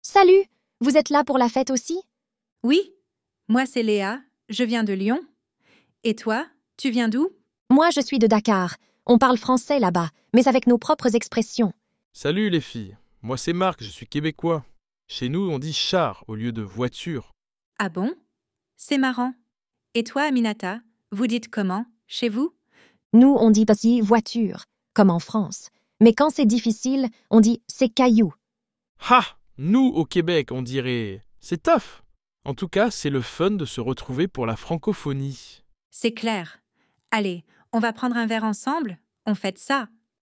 Dialog autentic între trei francofoni din trei continente, vocabular, quiz și un fun fact: franceza se vorbește pe 6 continente!